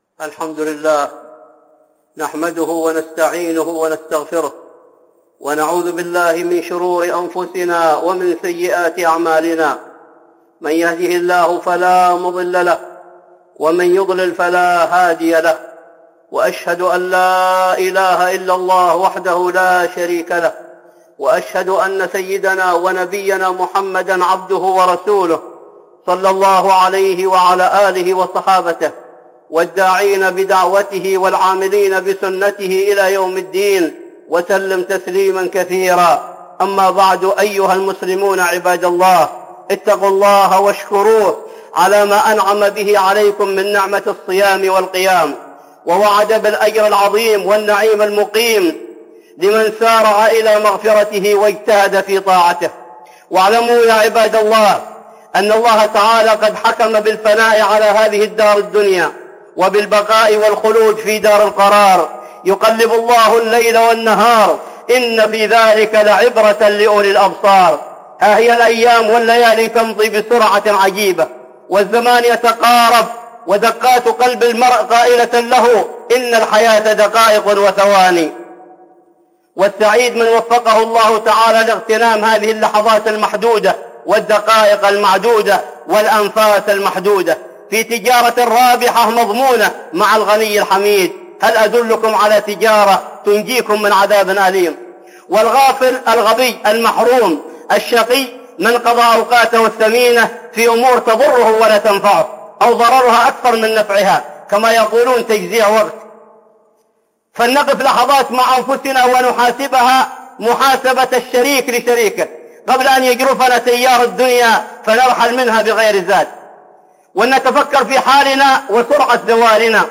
(خطبة جمعة) آخر رمضان